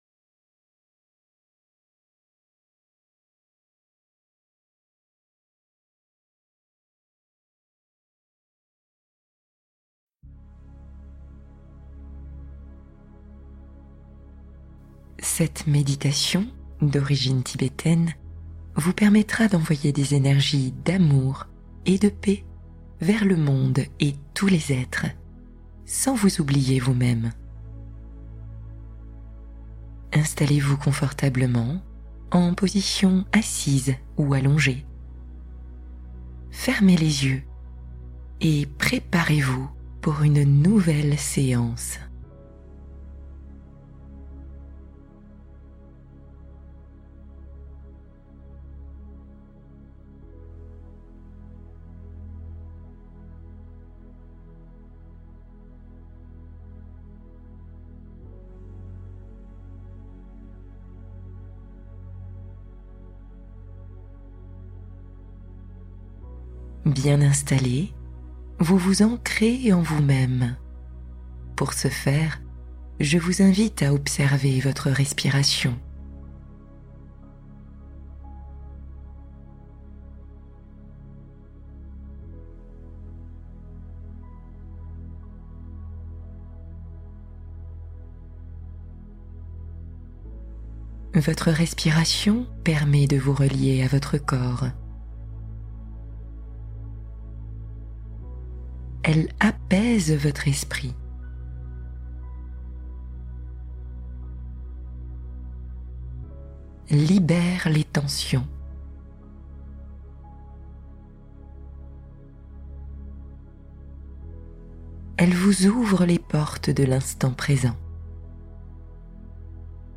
Guérissez votre cœur en 20 minutes | Méditation d’amour bienveillant profondément apaisante